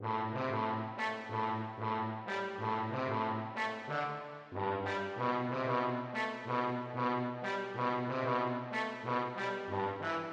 蹦蹦跳跳的大鼓
描述：蹦蹦跳跳的
标签： 93 bpm Dancehall Loops Drum Loops 1.74 MB wav Key : Unknown
声道立体声